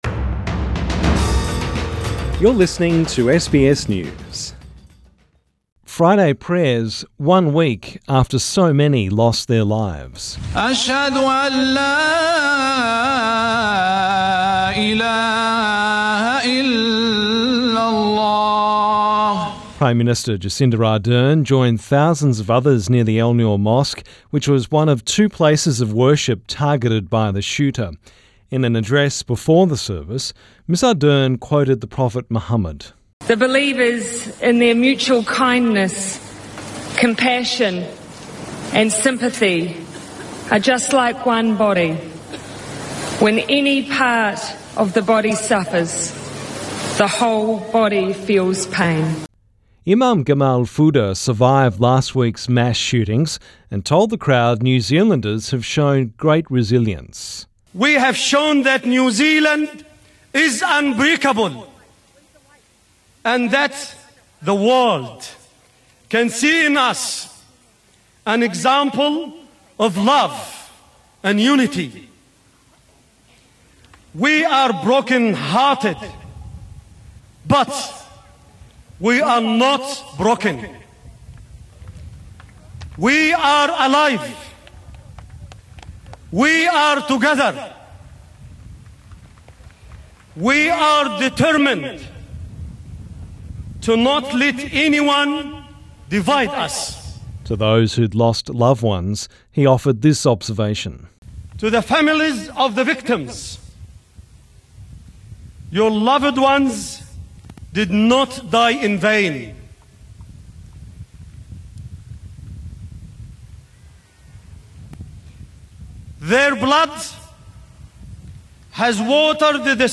New Zealanders have observed the Islamic call to prayer and observed two minutes of silence to remember those killed in the Christchurch mosque attacks.
Friday prayers at Hagley Park to mark a week since the deadly attacks on Christchurch mosques Source: AAP